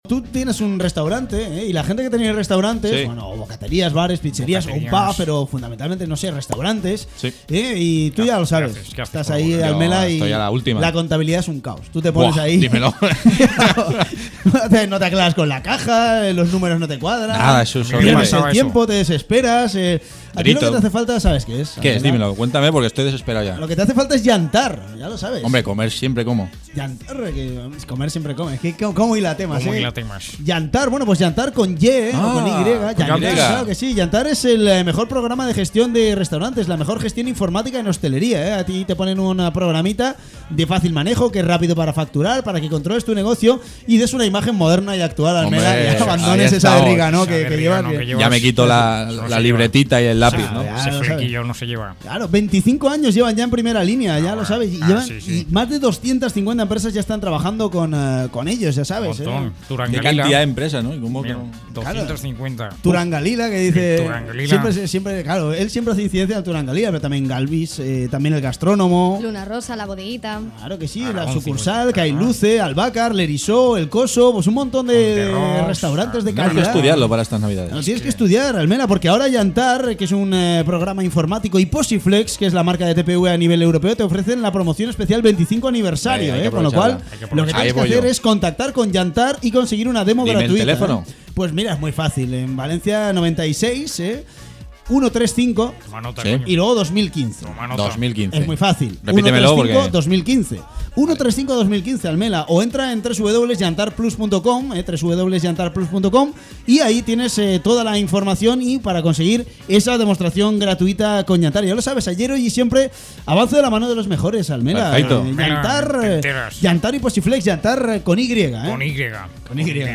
Escucha la Publicidad de YantarPlus Descarga la Publicidad de YantarPlus
promocion_yantar.mp3